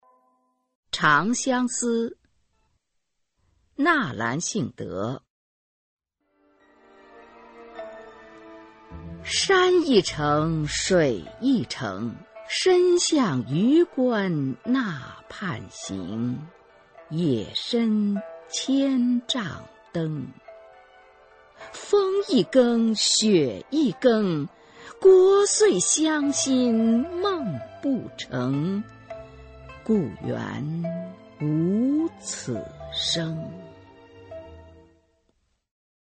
[清代诗词诵读]纳兰性德-长相思 配乐诗朗诵